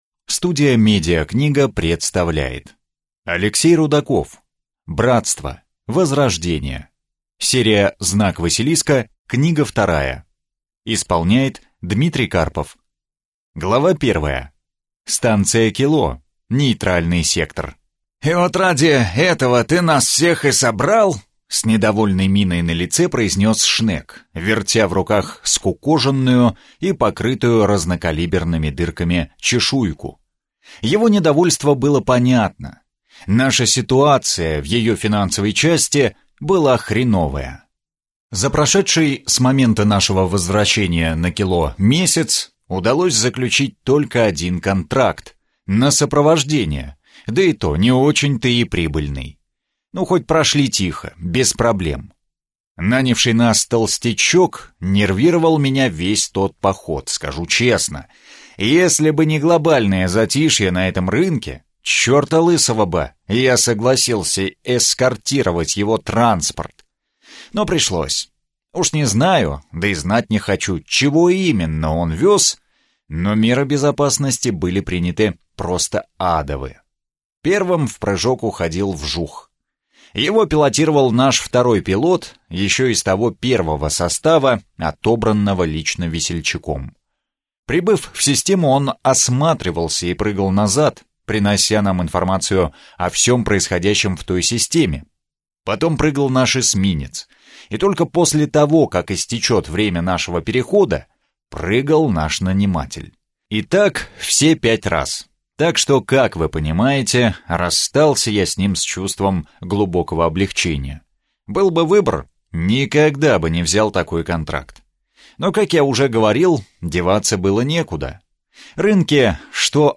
Аудиокнига Братство: Возрождение | Библиотека аудиокниг